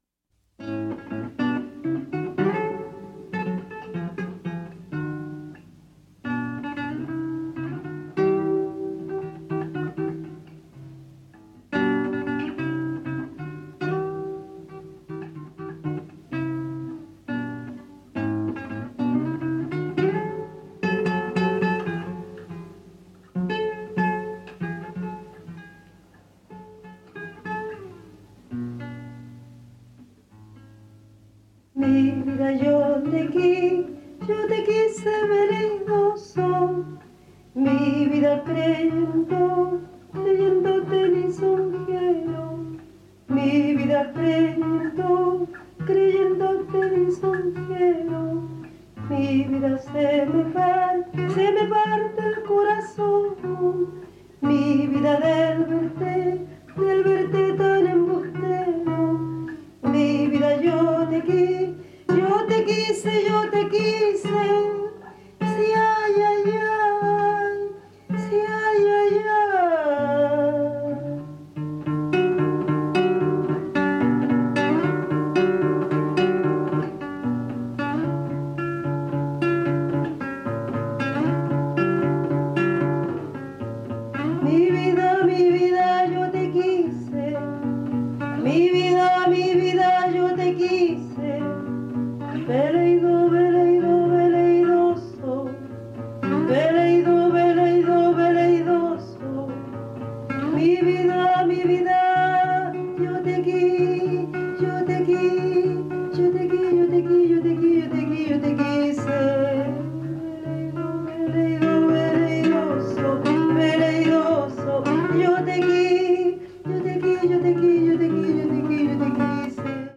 軽やかな舞曲や哀愁を帯びた弔歌などに加えて、チリ民謡のマナーで自作曲をも制作！